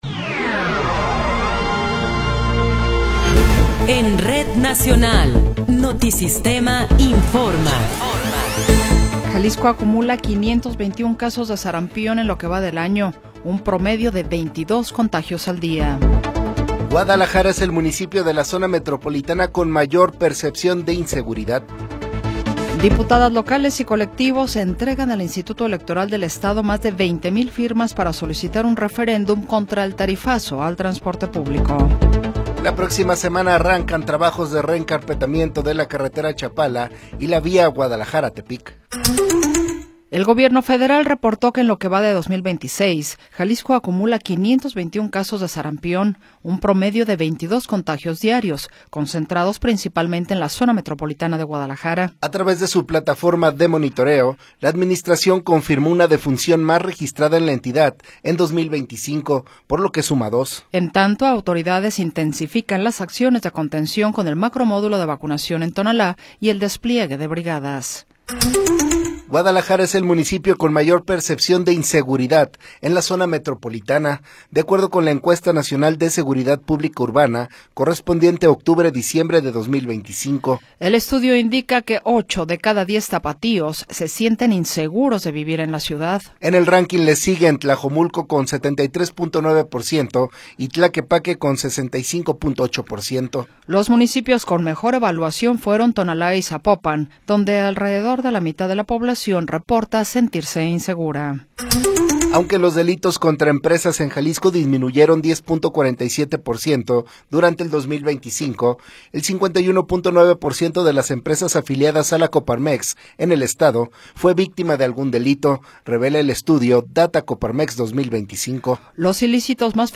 Noticiero 20 hrs. – 23 de Enero de 2026